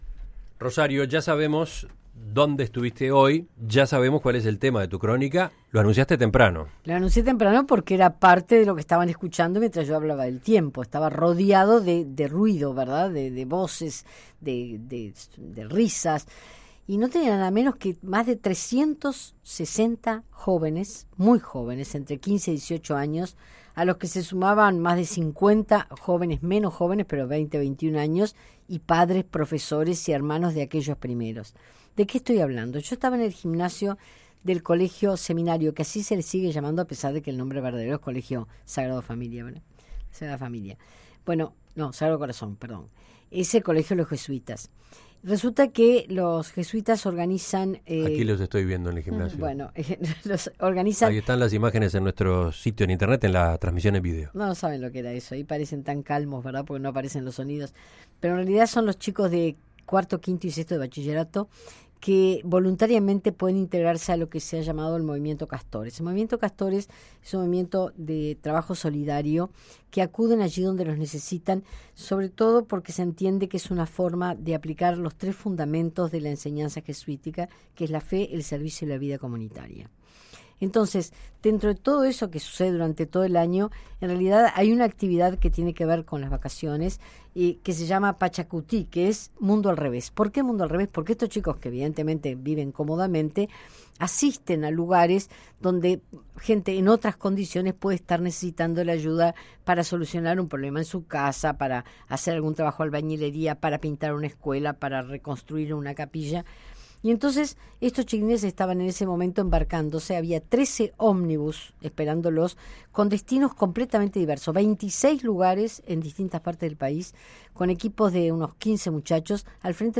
En el siguiente audio podrás escuchar la cobertura que la Radio Uruguaya ‘En perspectiva’ ha hecho del día en que partían hacia el campamento.